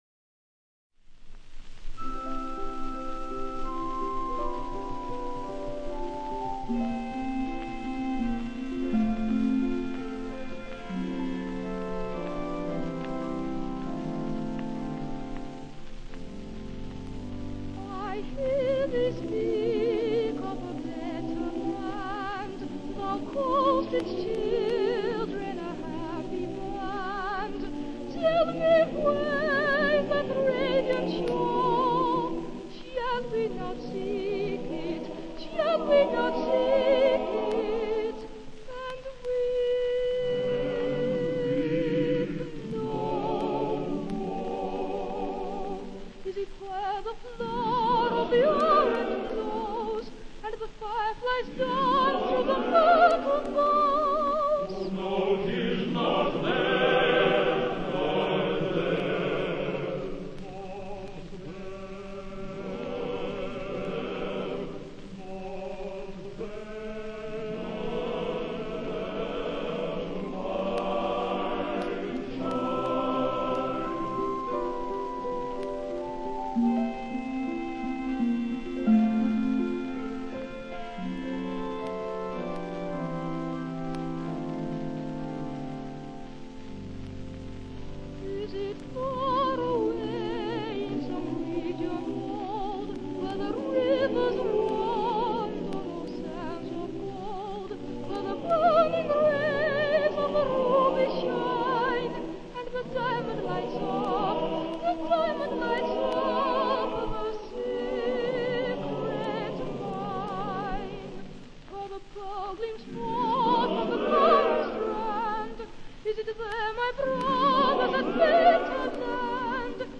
Great Boy Sopranos of the Early Twentieth Century
organ
Rec. Grande Theatre, Llandudno  July 1942